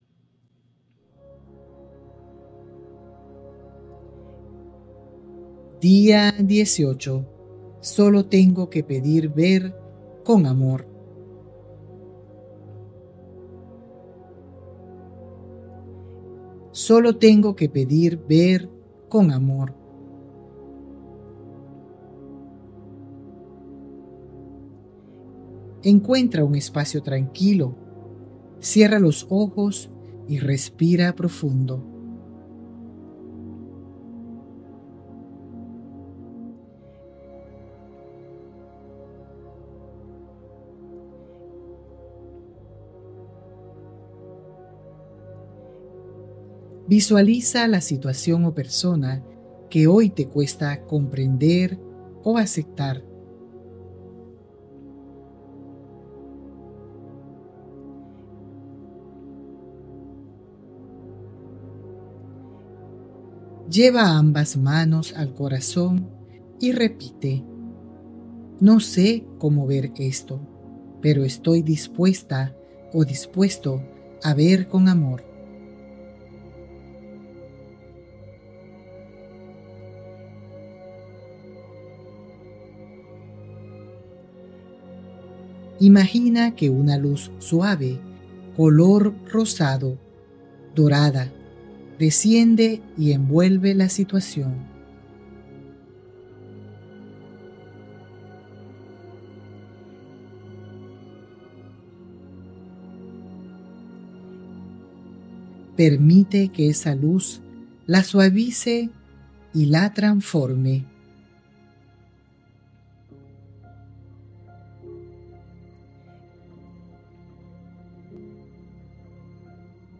🎧 Meditación Guiada: «Solo tengo que pedir ver con amor»